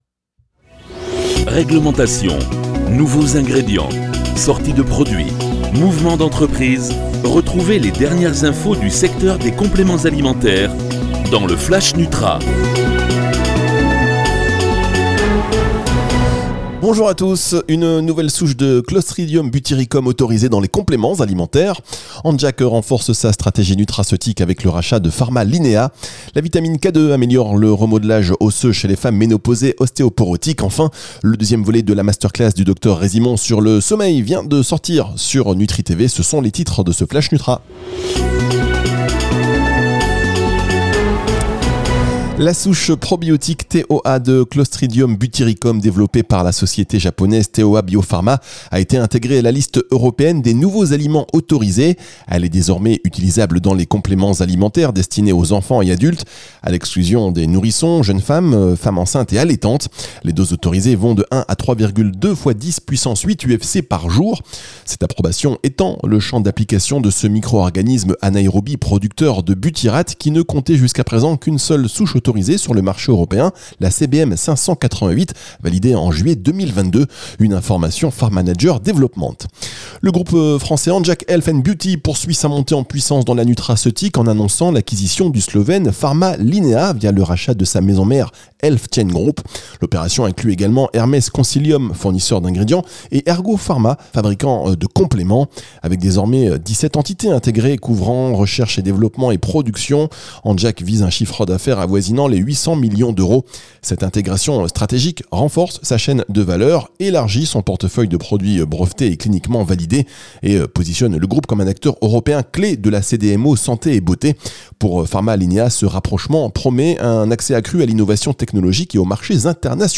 Infos réglementaires, nouveaux ingrédients, lancement de produits, innovation, mouvements d'entreprises, politique RSE, recrutement...Toutes les news de la nutraceutique condensées dans un flash audio.